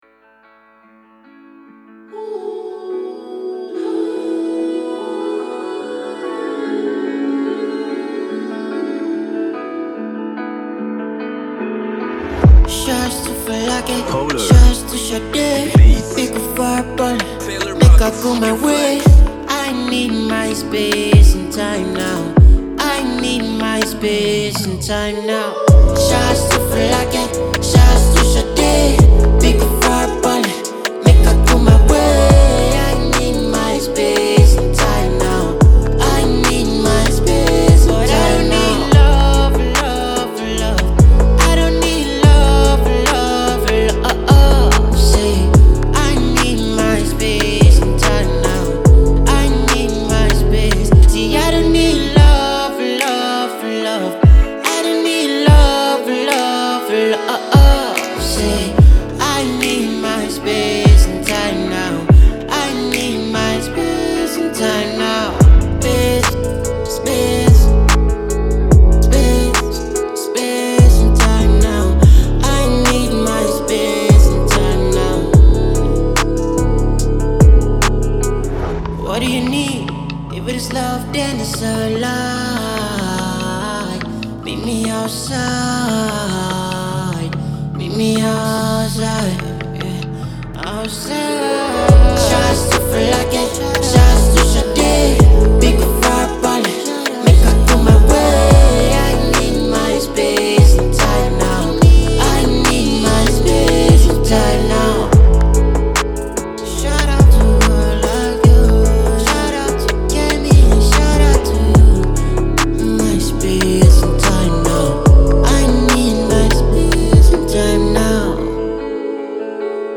a fine blend of trap and afro